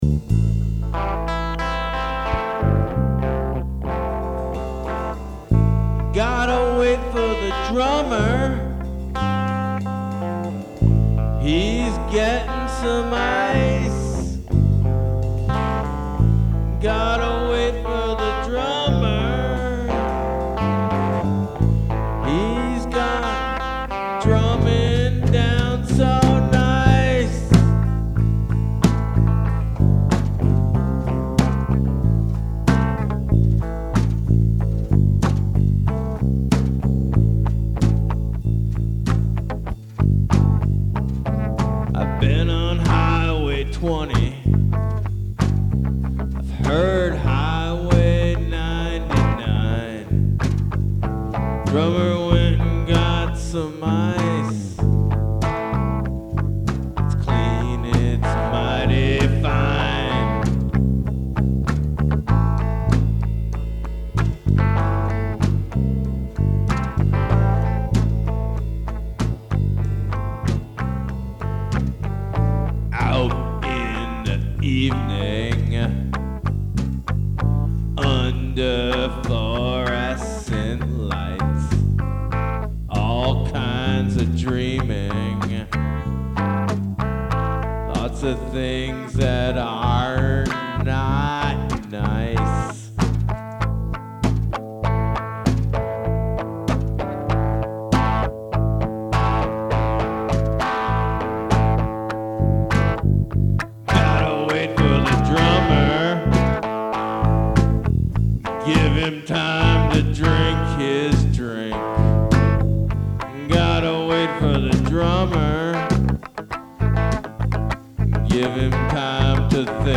from naples, recorded at goof city December 18, 2008. two songs improvised, two versions of vu's what goes on: